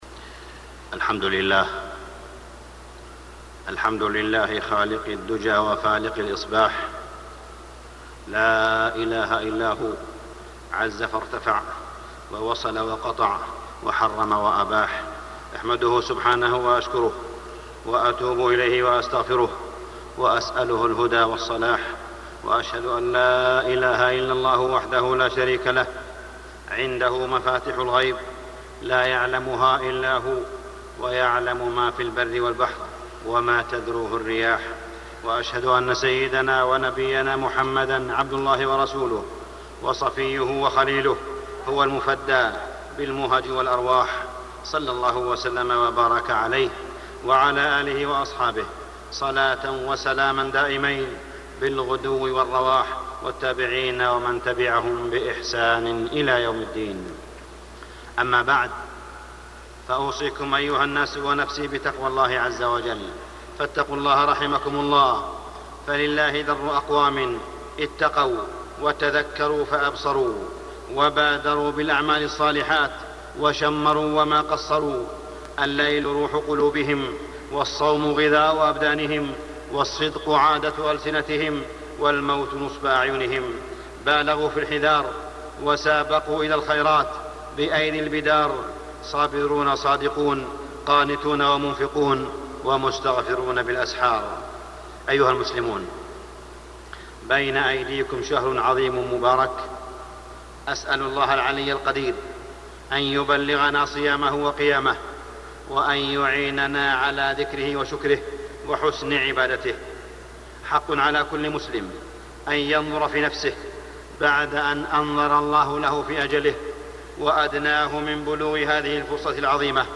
تاريخ النشر ٢٦ شعبان ١٤٢٦ هـ المكان: المسجد الحرام الشيخ: معالي الشيخ أ.د. صالح بن عبدالله بن حميد معالي الشيخ أ.د. صالح بن عبدالله بن حميد نرقب وصولك يا رمضان